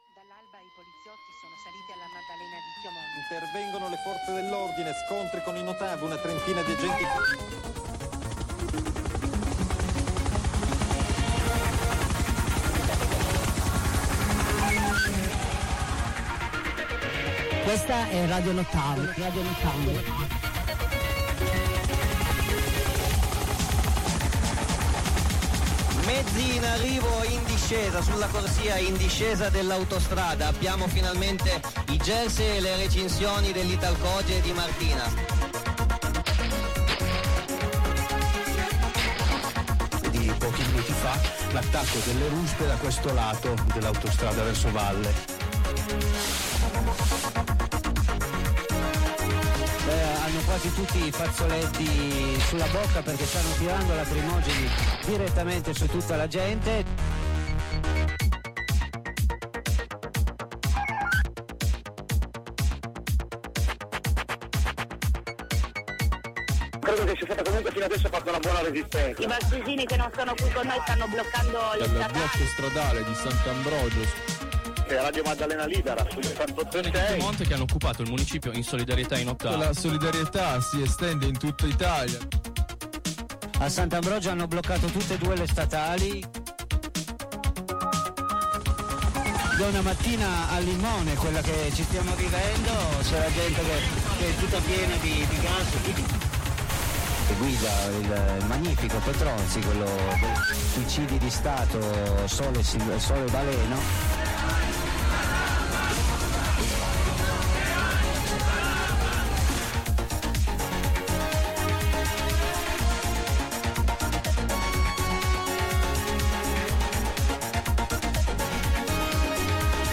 Trasmissione del 19/12/2024 dalla piazza del mercato di Susa